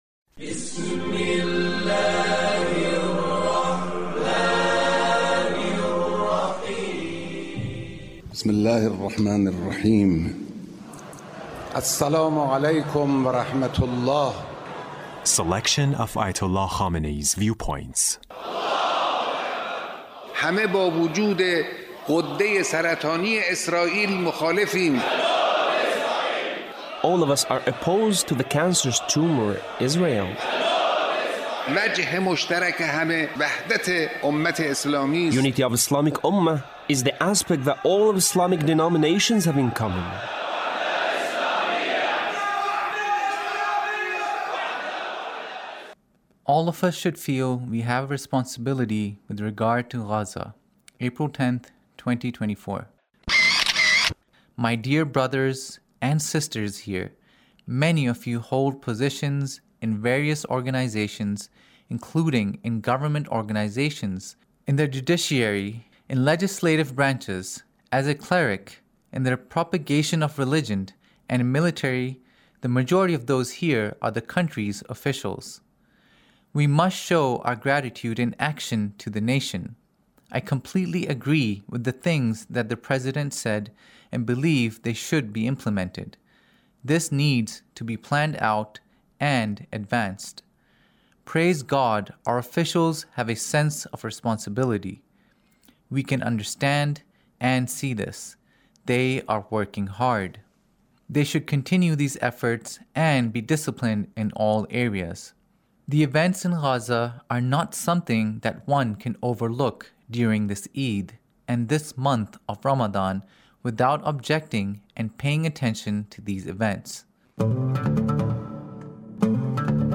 Leader's Speech in a Meeting with the Government Officials